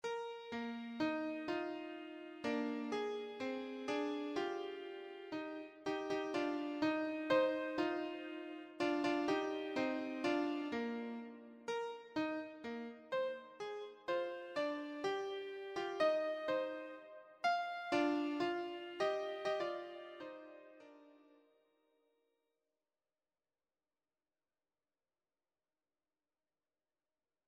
choir SA
Electronically Generated
Sibelius file